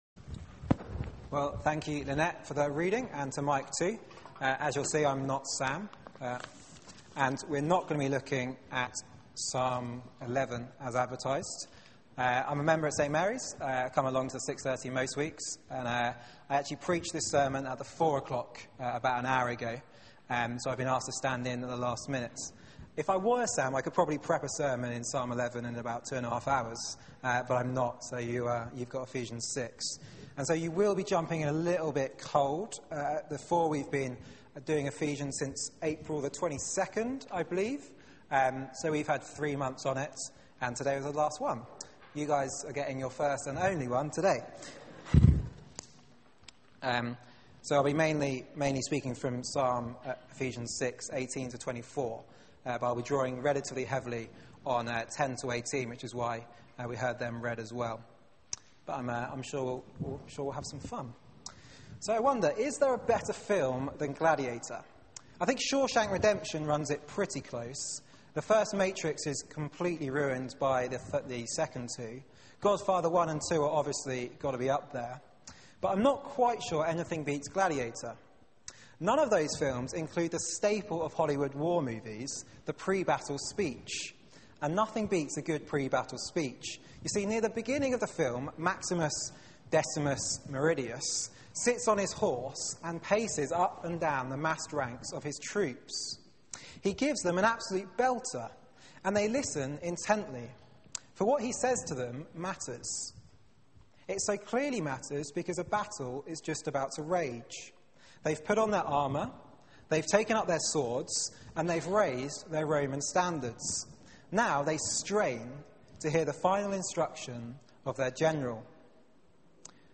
Media for 6:30pm Service on Sun 22nd Jul 2012 18:30 Speaker
Sermon